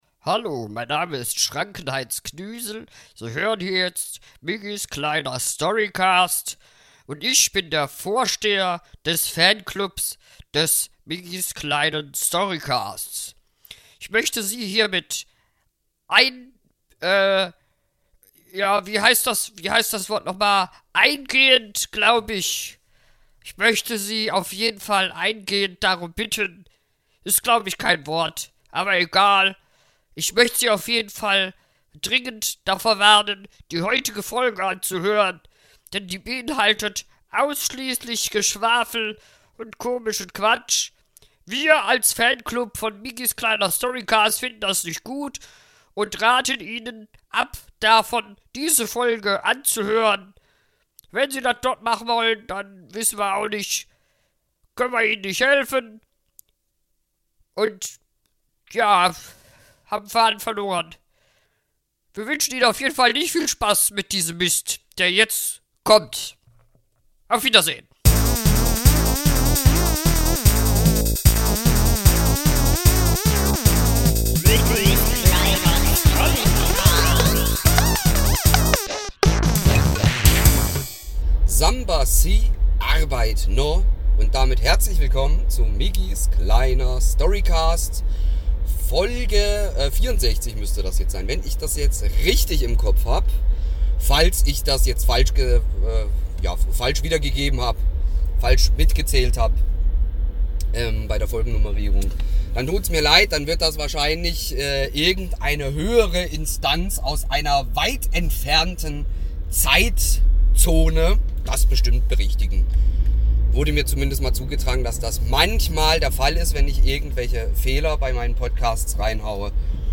Denn während er durch die ganze Republik gereist ist, hatte er stets sein mobiles Aufnahmestudio dabei und hat seine Reise bis ins kleinste Detail dokumentiert. Den Zusammenschnitt könnt ihr in der heutigen Folge hören und somit selbst etwas Urlaubsluft schnuppern.